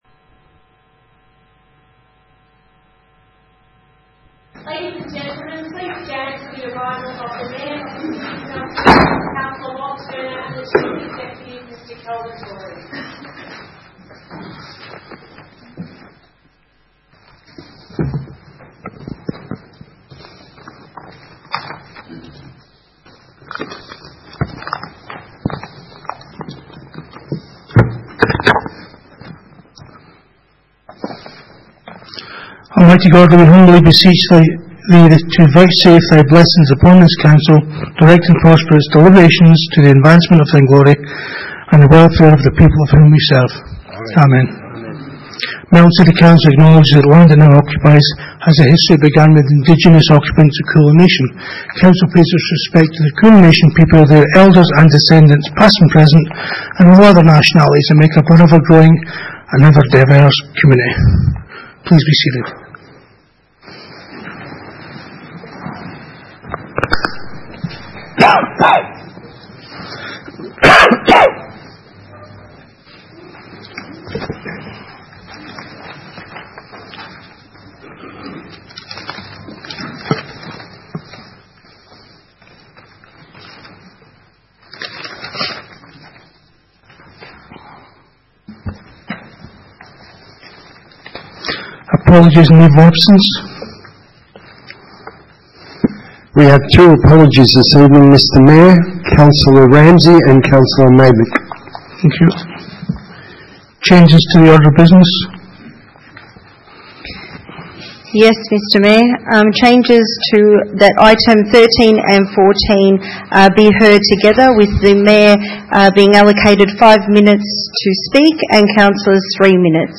Ordinary Meeting 19 August 2019
Council Chambers, Melton Civic Centre, 232 High Street, Melton, 3337 View Map